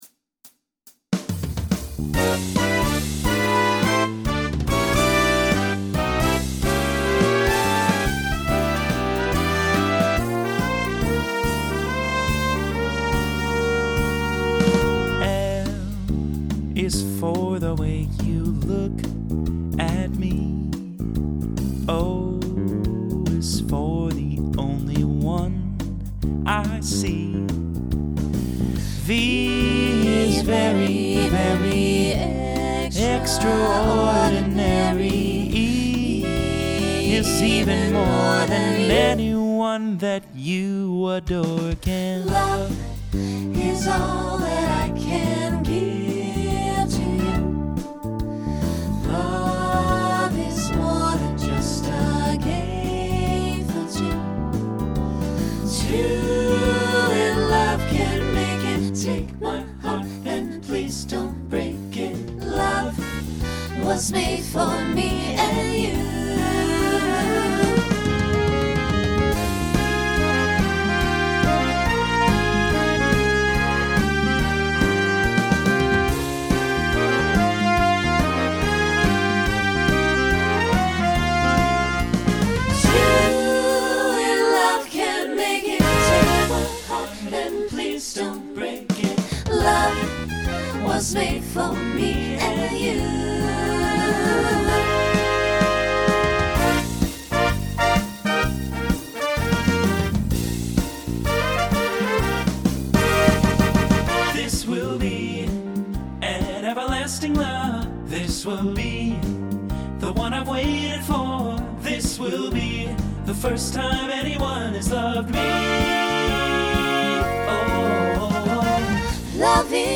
Pop/Dance , Swing/Jazz
Voicing SATB